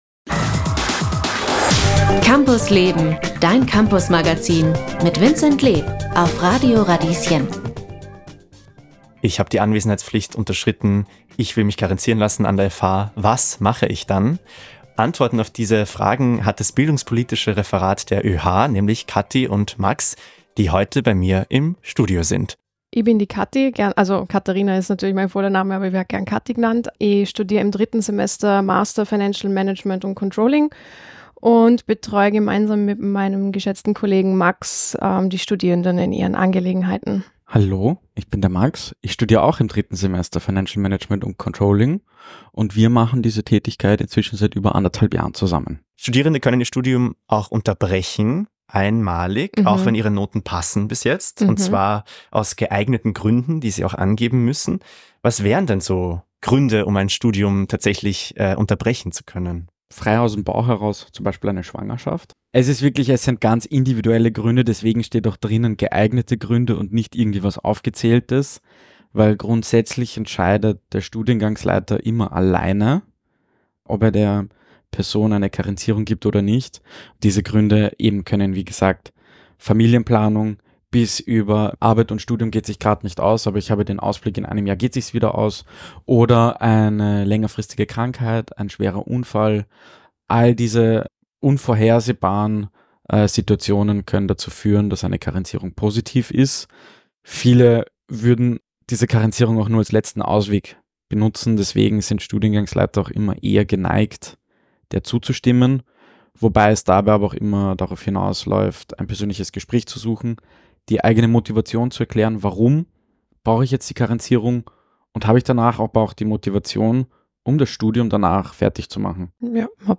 Diese Podcast-Folge ist ein Ausschnitt aus der Campus Leben-Radiosendung vom 23. Oktober 2024.